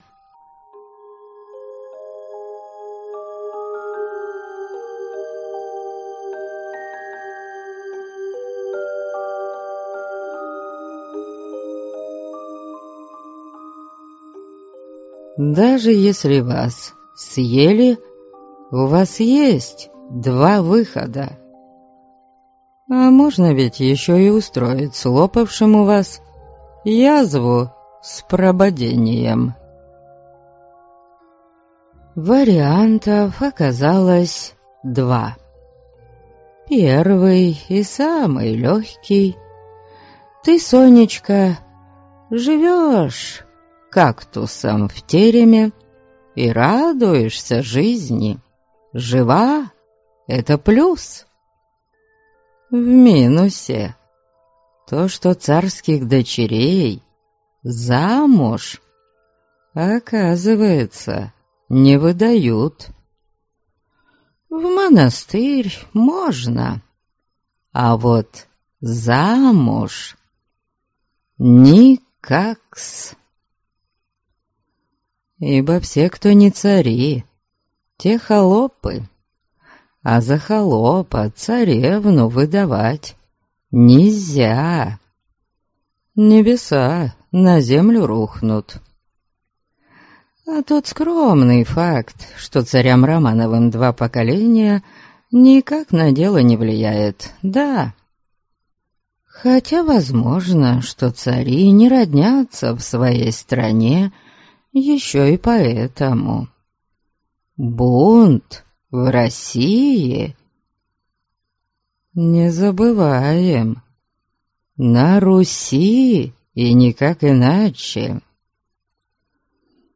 Аудиокнига Азъ есмь Софья. Сестра - купить, скачать и слушать онлайн | КнигоПоиск